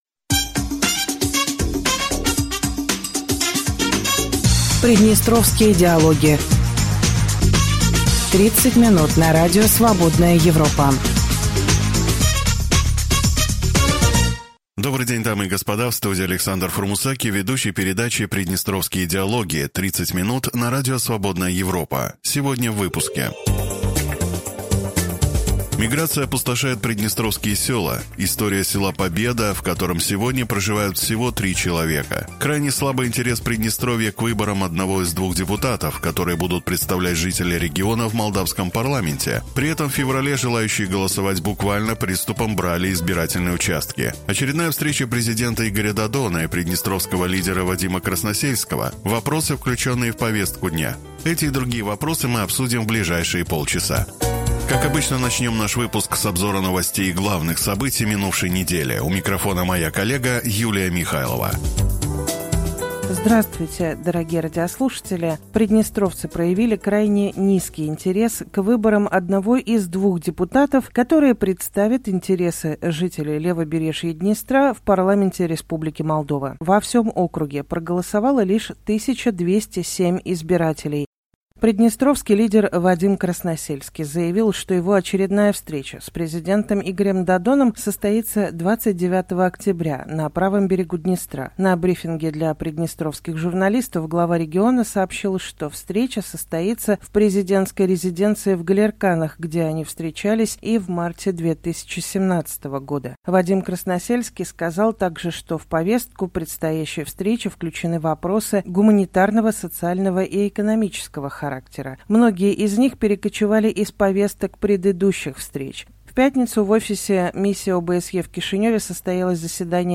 Дорогие радиослушатели, добрый день.